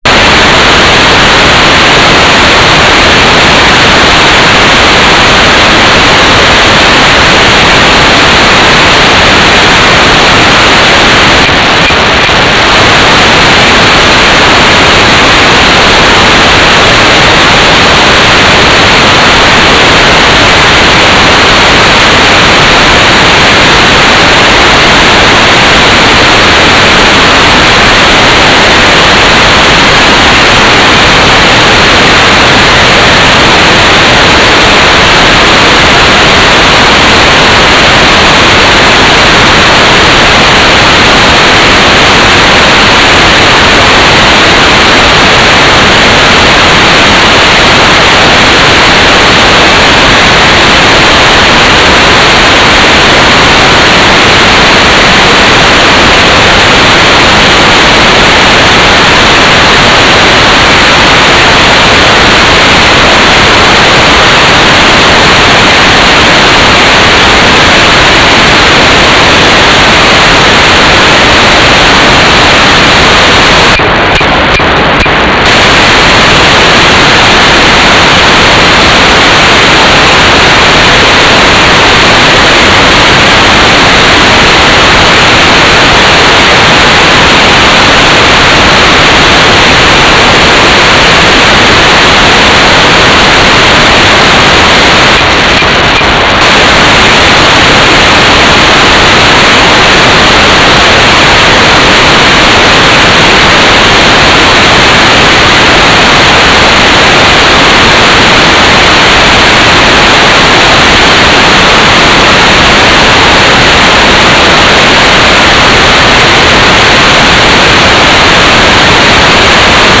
"transmitter_description": "Mode U - GMSK 4k8 AX.25 TLM",